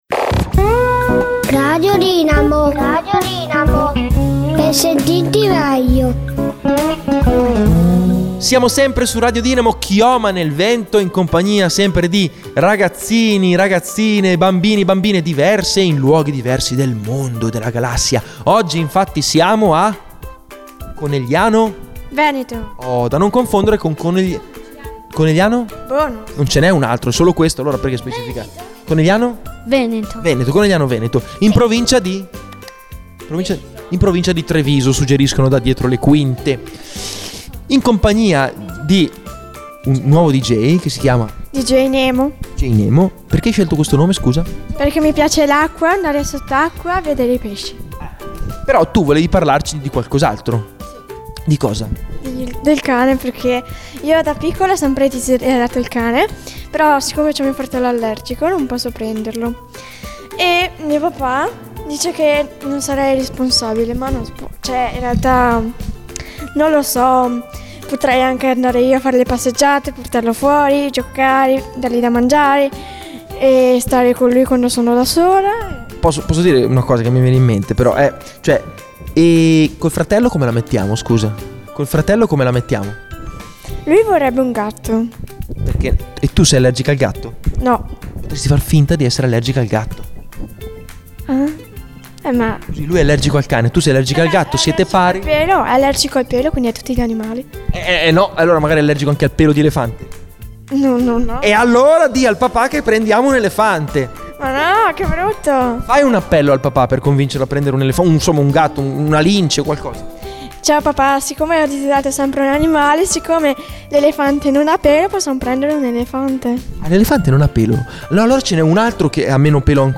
MINI-INTERVISTA!!